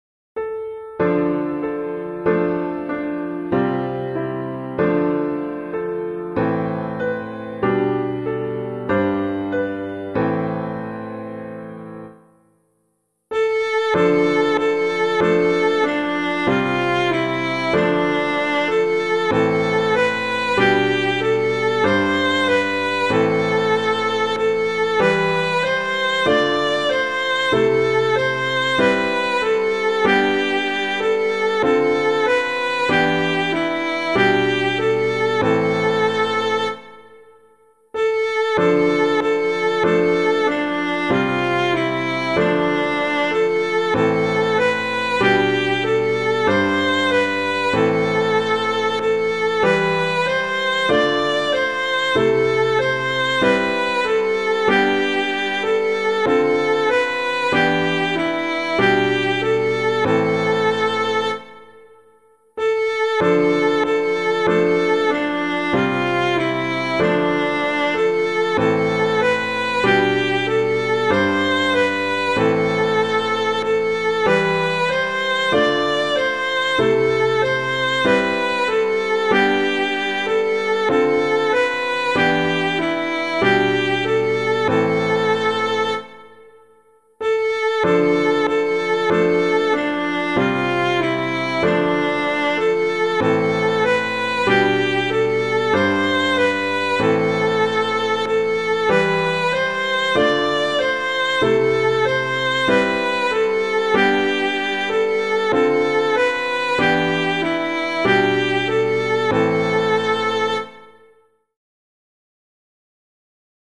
Composer:    Chant, mode I.
piano
O Sun of Justice Jesus Christ [Scagnelli - JESU DULCIS MEMORIA] - piano.mp3